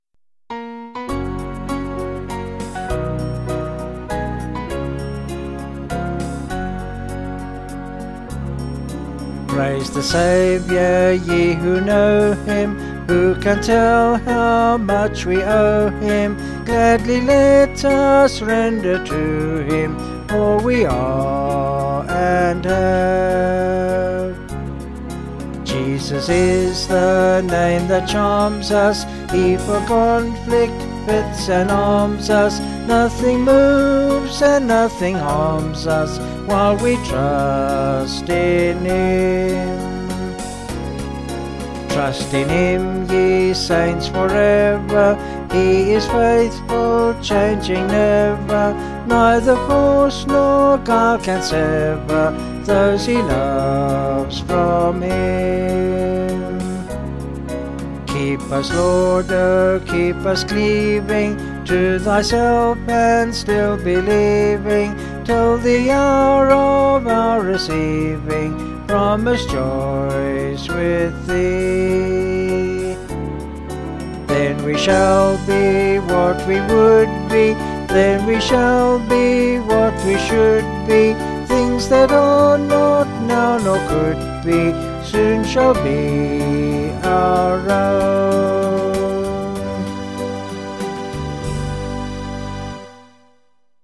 Vocals and Band
263.1kb Sung Lyrics